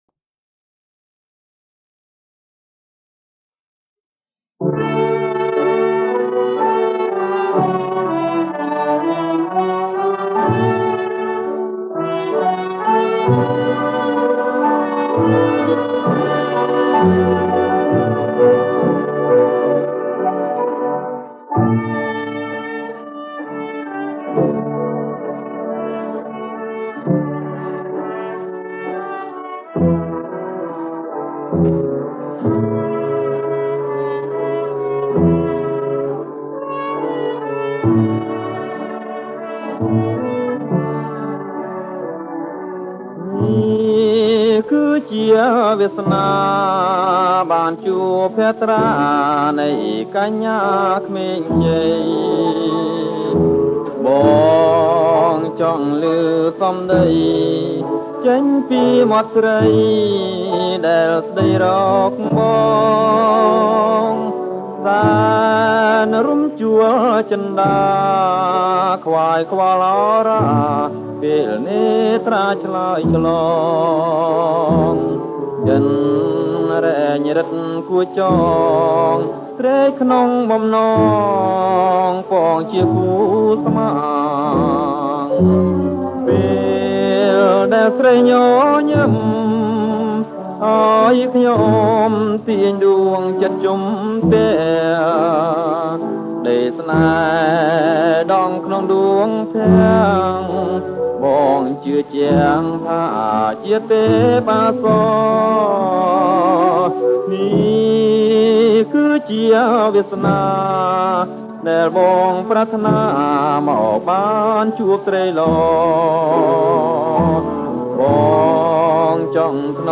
ថតផ្ទាល់ពីថាស (Vinyl)